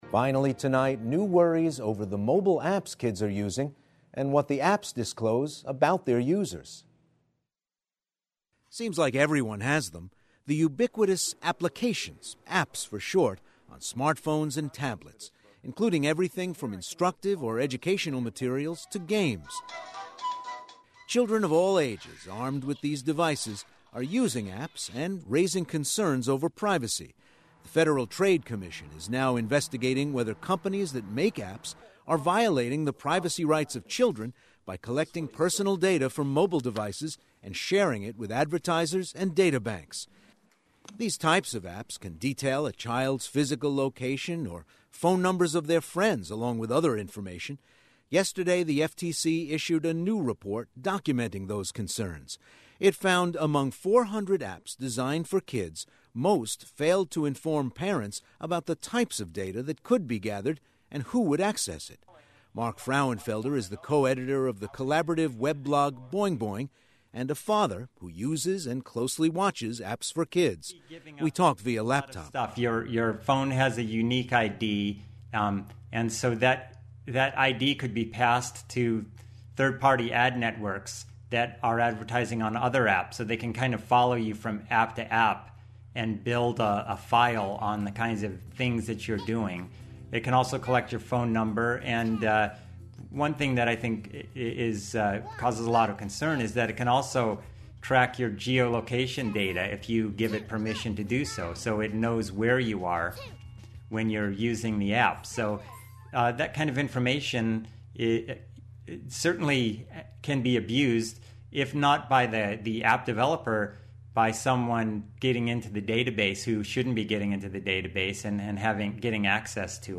We talked via laptop.